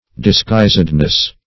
Disguisedness \Dis*guis"ed*ness\, n. The state of being disguised.